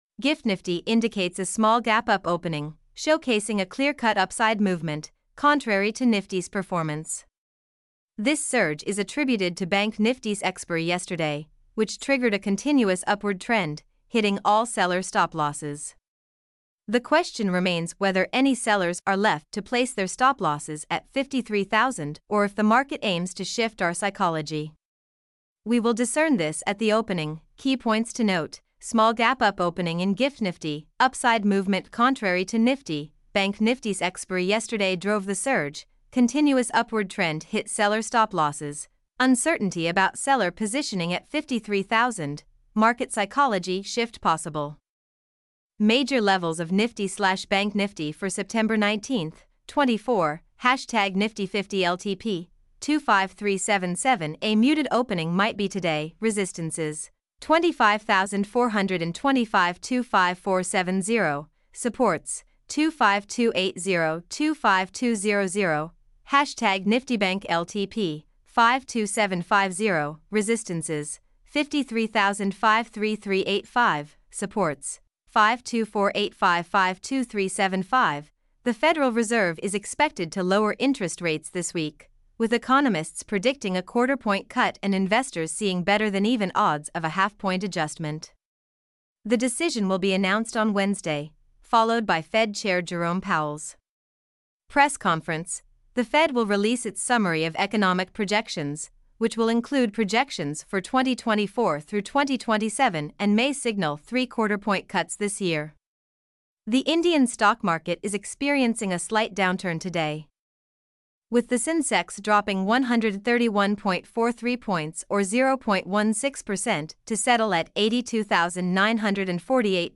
mp3-output-ttsfreedotcom-4.mp3